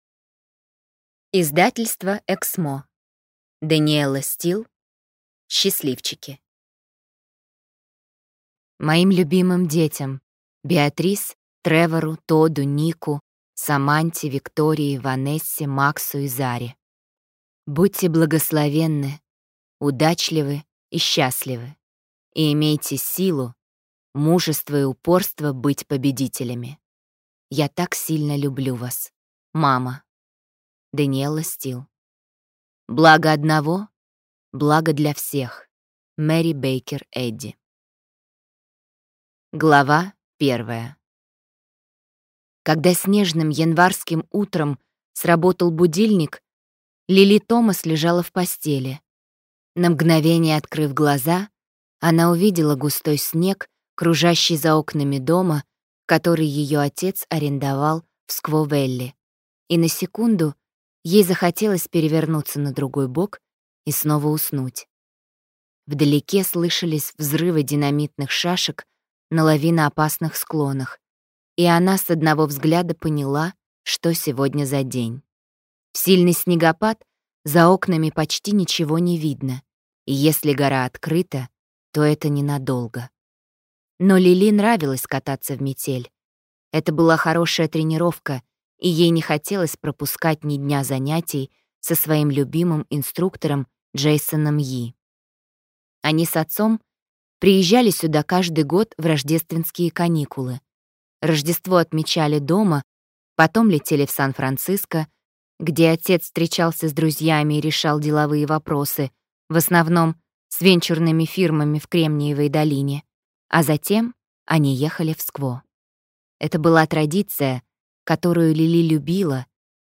Аудиокнига Счастливчики | Библиотека аудиокниг